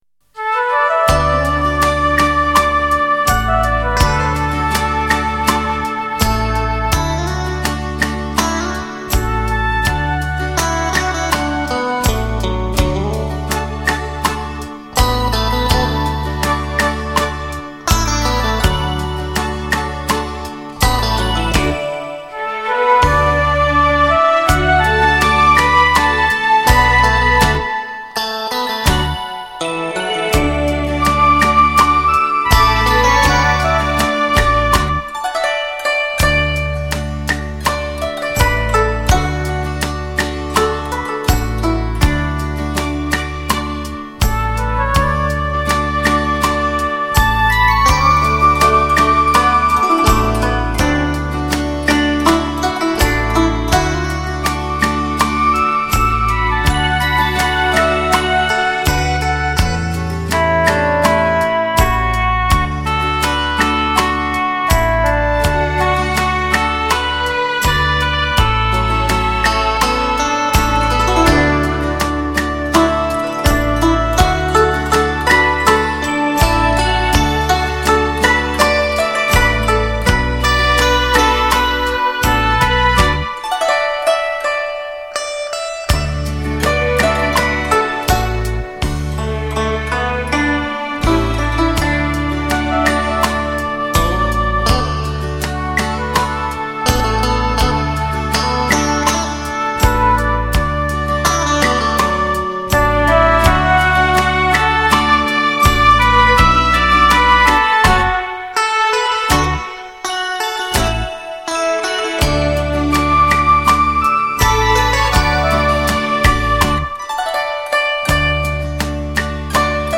绕场立体音效 发烧音乐重炫